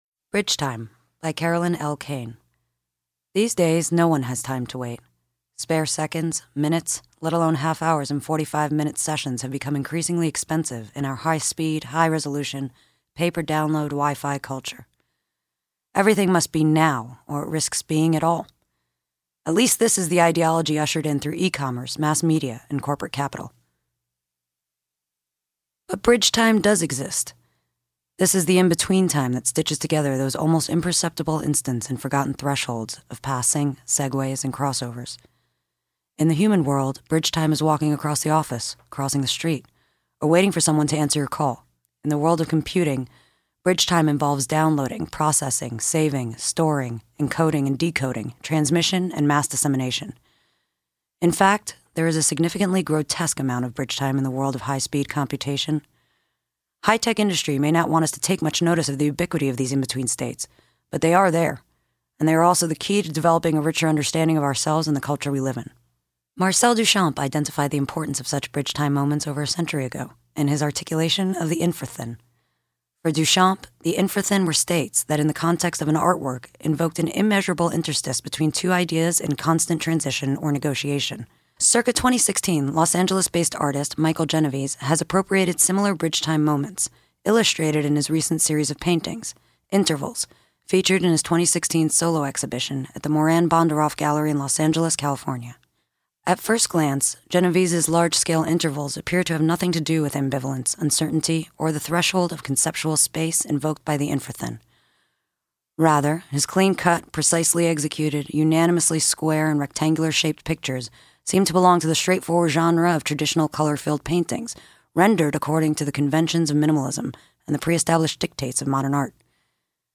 Bridge Time by Carolyn L. Kane read by Natasha Lyonne.mp3